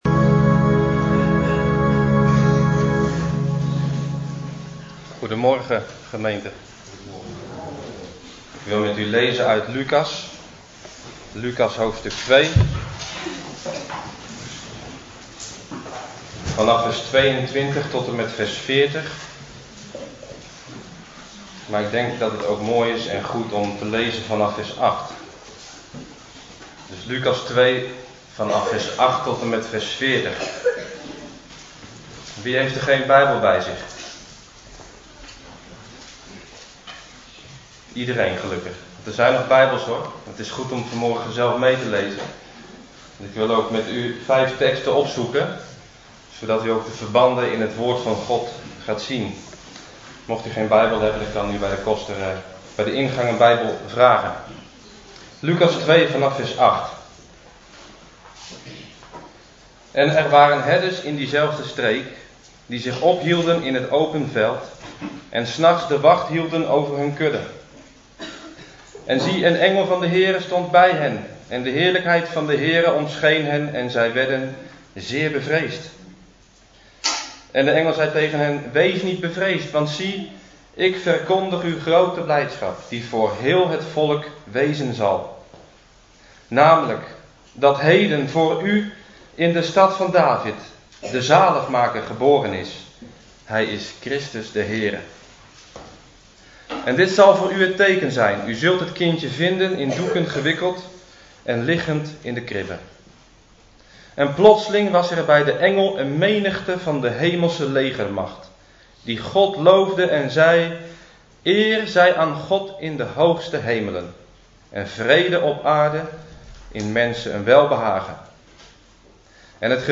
Kerstdienst – Reformatorische Baptistengemeente Heuvelrug